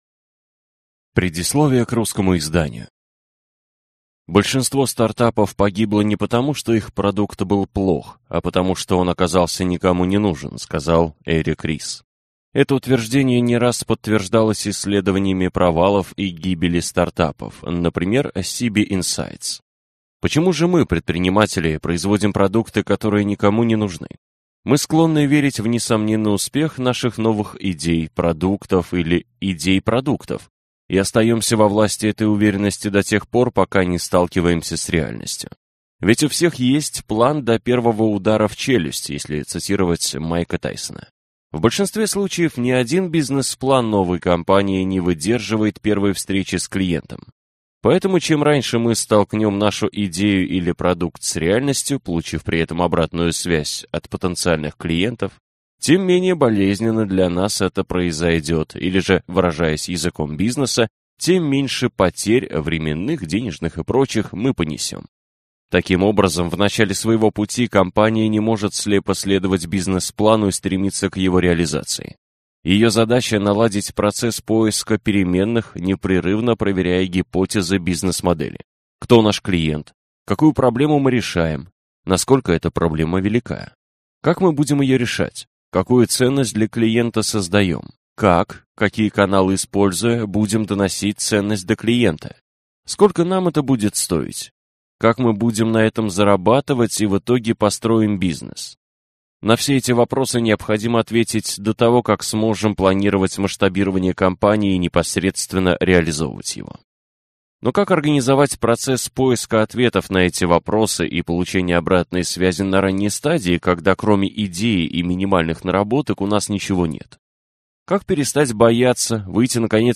Аудиокнига Спроси маму: Как общаться с клиентами и подтвердить правоту своей бизнес-идеи, если все кругом врут?